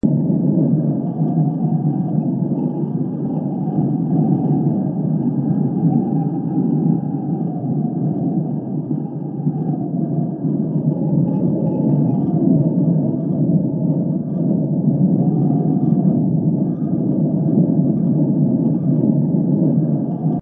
windloop.wav